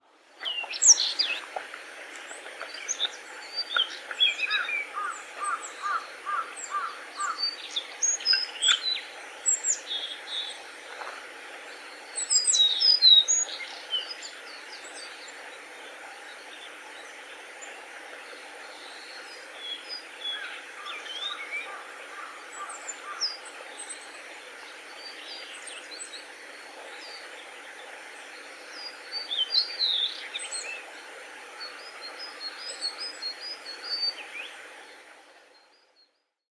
【録音②】　ジョウビタキ（さえずり）
2026年3月29日　嬬恋村
ヒリリリ　チョピリチョピリなどと高音で鳴く
（動画から音源を取り出したためあまりいい音ではない）